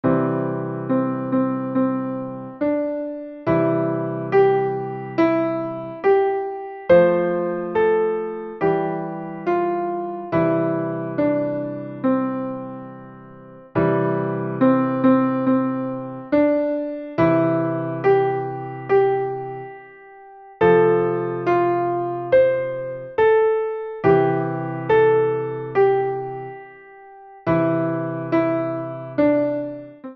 カテゴリー: ピアノ伴奏